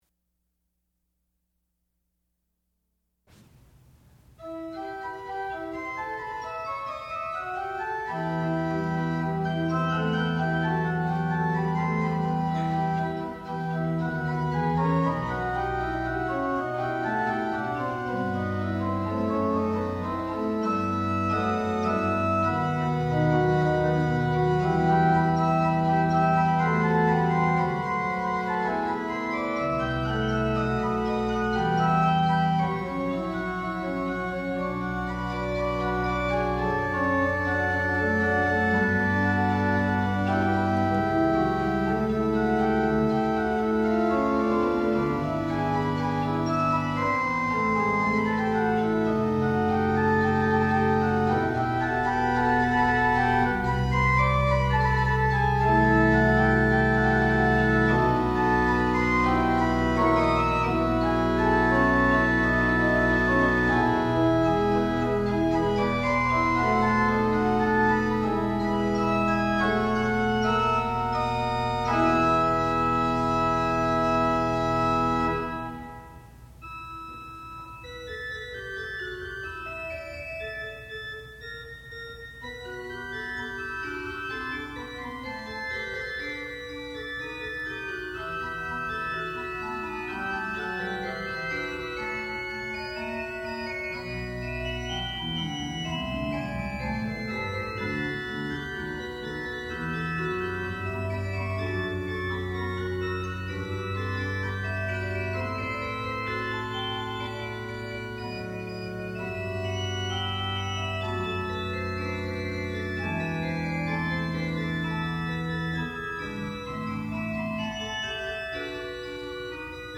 classical music
Graduate Recital
organ